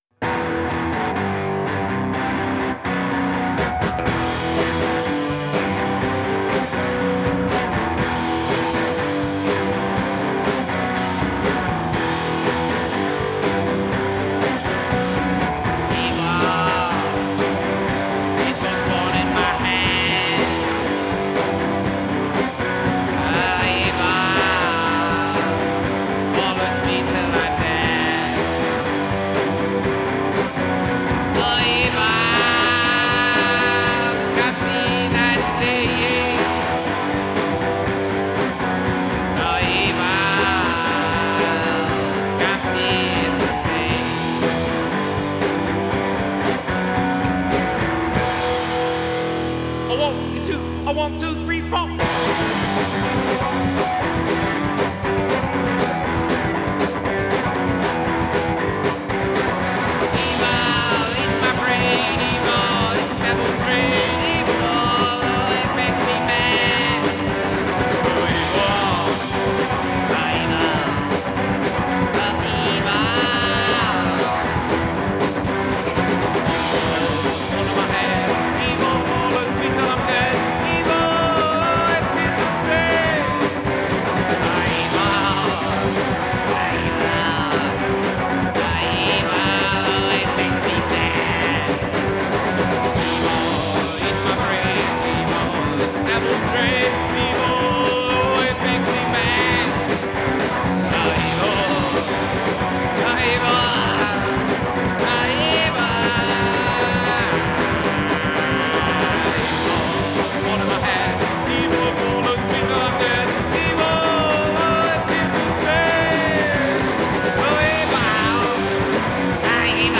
Genre: Psychobilly, Rockabilly, Country